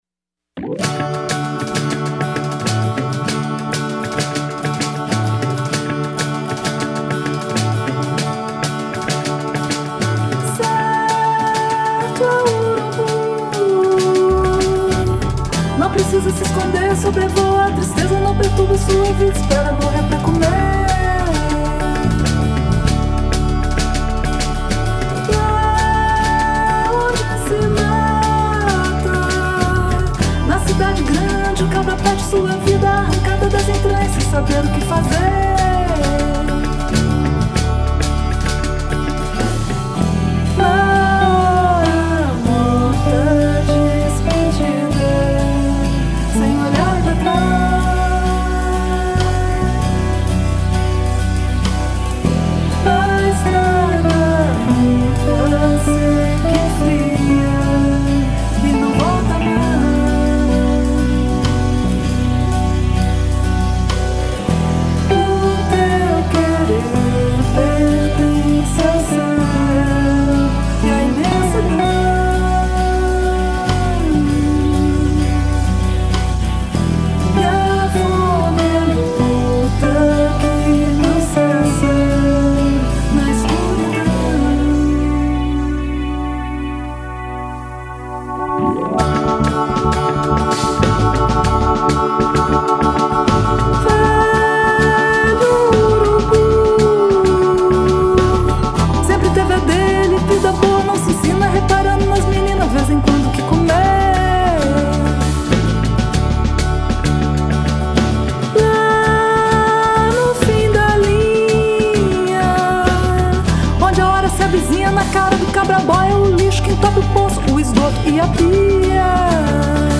violão de aço
baixo
guitarra e teclados
bateria
pandeiro e triângulo